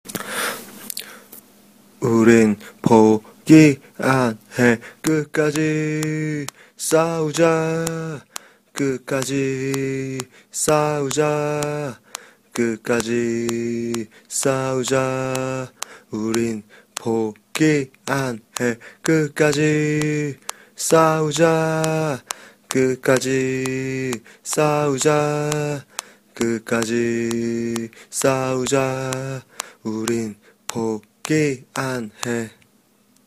(야밤에 핸드폰 잡고 혼자 녹음 했으니 노약자 및 심장이 약하신 분들은 혼자 듣지 마시기 바랍니다)